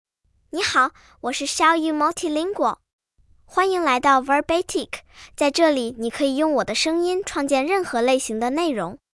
Xiaoyou Multilingual — Female Chinese (Mandarin, Simplified) AI Voice | TTS, Voice Cloning & Video | Verbatik AI
Xiaoyou Multilingual is a female AI voice for Chinese (Mandarin, Simplified).
Voice sample
Listen to Xiaoyou Multilingual's female Chinese voice.
Xiaoyou Multilingual delivers clear pronunciation with authentic Mandarin, Simplified Chinese intonation, making your content sound professionally produced.